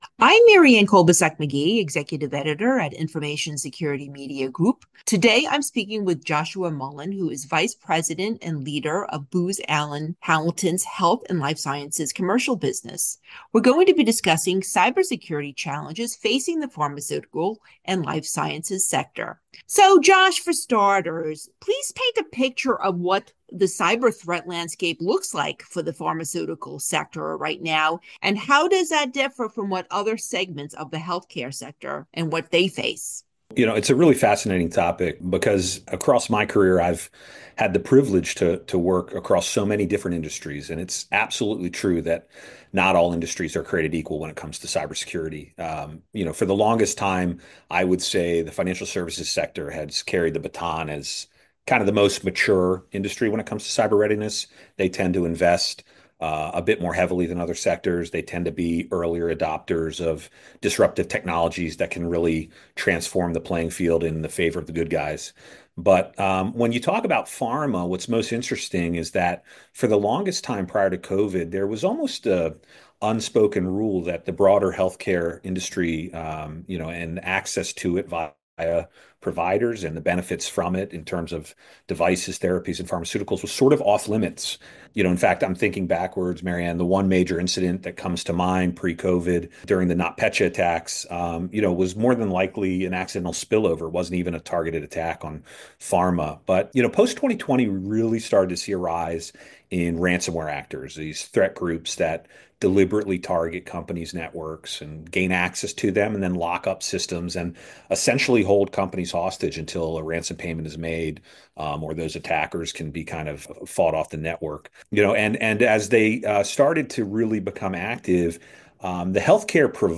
Exclusive, insightful audio interviews by our staff with creditunion/security leading practitioners and thought-leaders.
Audio interviews with information security professionals.